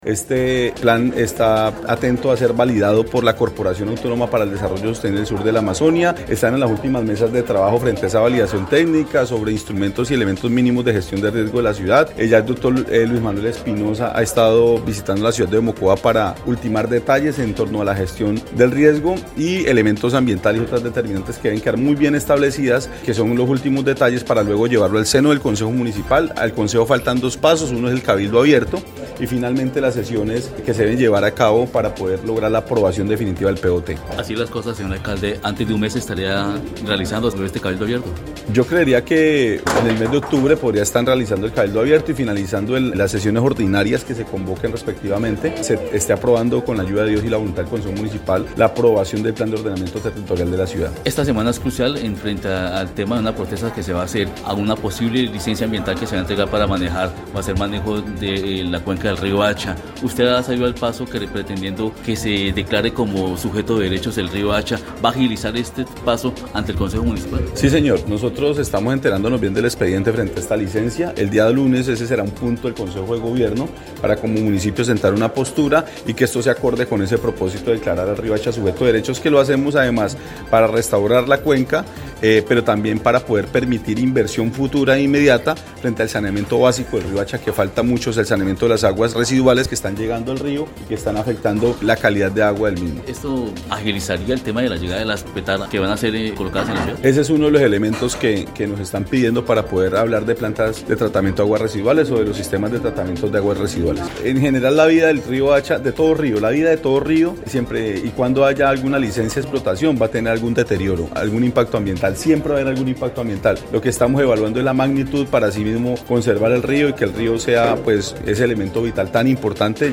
De acuerdo con Marlon Monsalve Ascanio, alcalde del municipio de Florencia, el nuevo Plan de Ordenamiento Territorial, busca preservar fuentes hídricas tan importantes como el Río Hacha, lo cual fortalece la propuesta de declararlo sujeto de derechos.
01_ALCALDE_MONSALVE_ASCANIO_POT_RIO.mp3